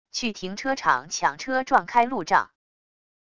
去停车场抢车撞开路障wav音频